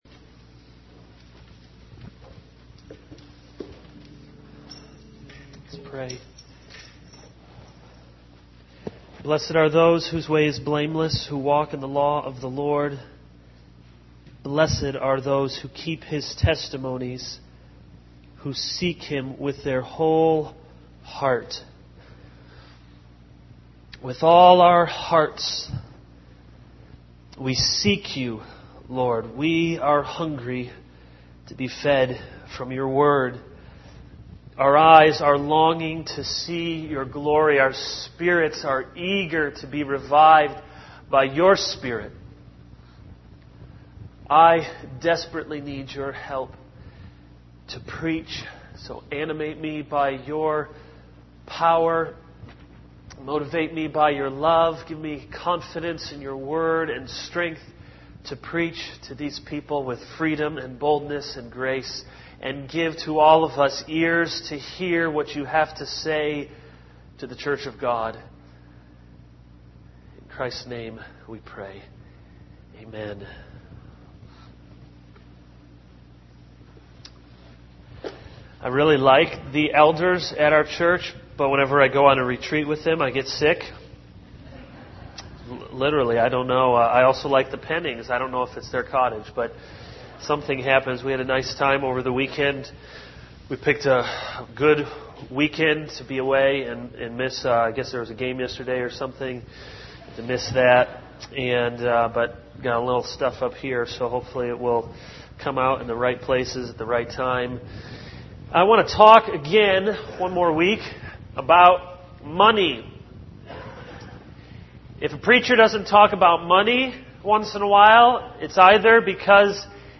This is a sermon on 2 Corinthians 9:6-15.